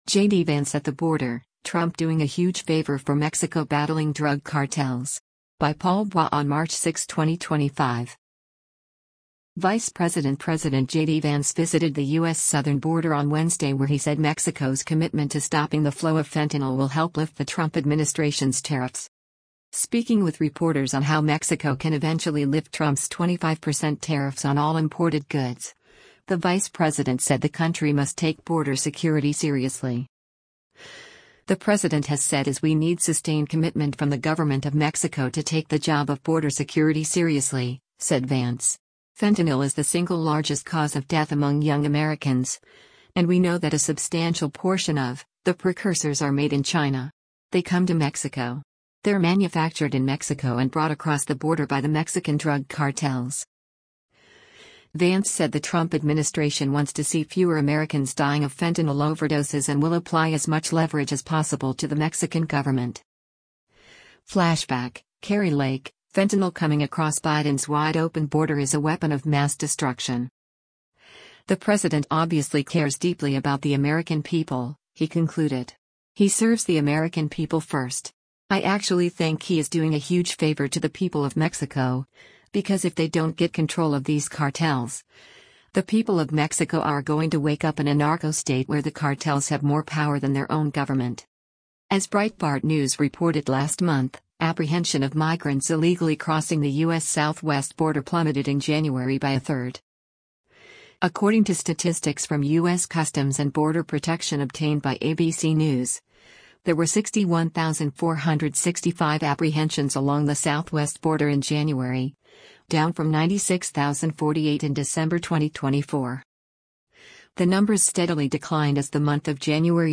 US Vice President JD Vance speaks to the press as he tours the US-Mexico border at Eagle P